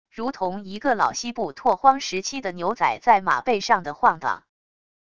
如同一个老西部拓荒时期的牛仔在马背上的晃荡wav音频